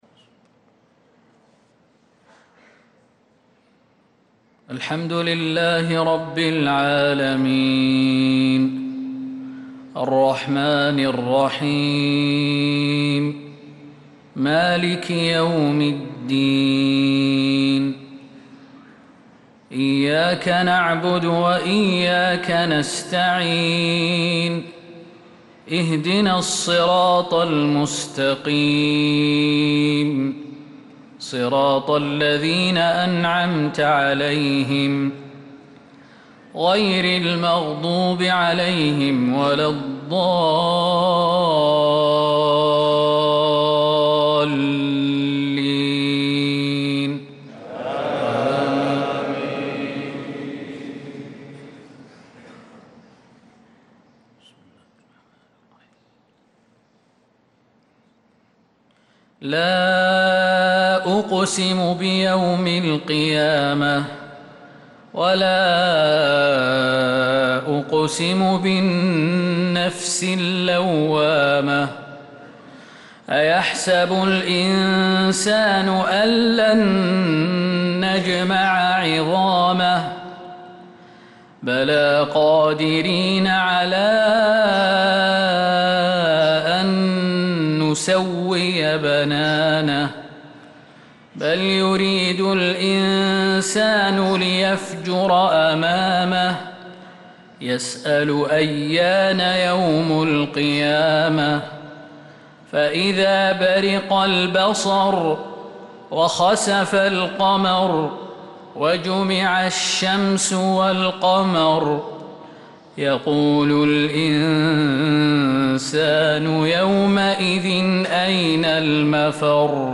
صلاة الفجر للقارئ خالد المهنا 14 ذو الحجة 1445 هـ